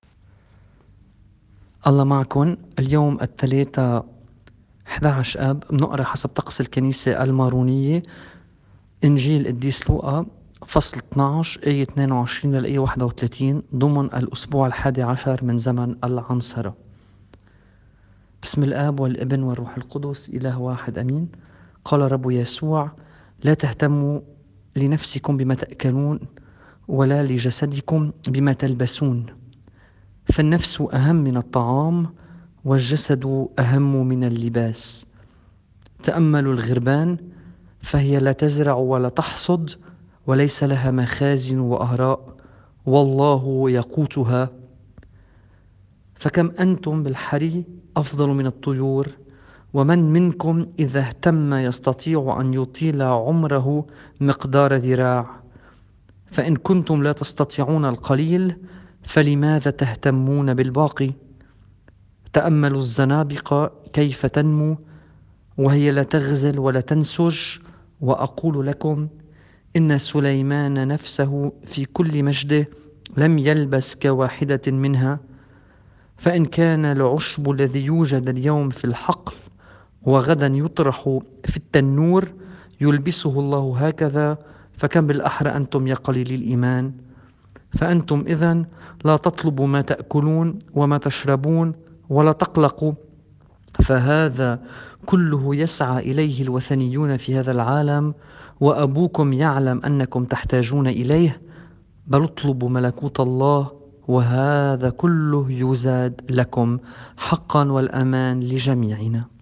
الإنجيل بحسب التقويم الماروني